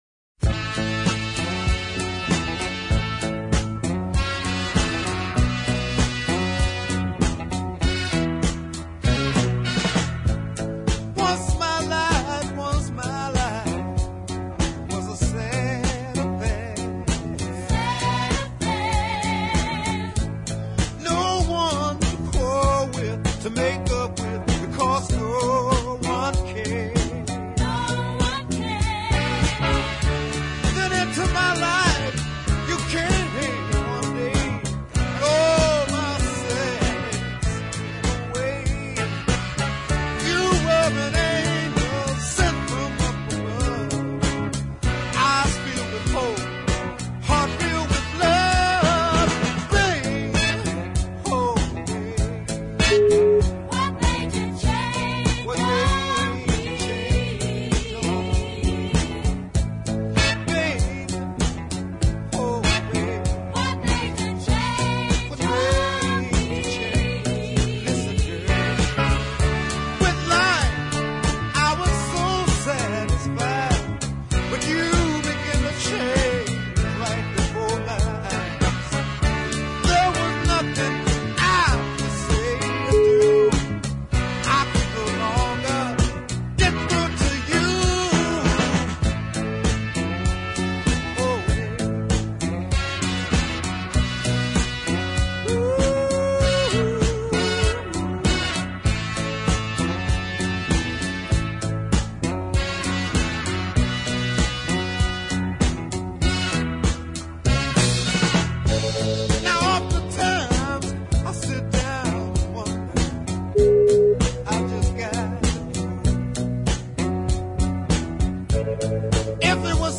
rolling, mid-paced
but in retrospect is a fine piece of soul. Fine melody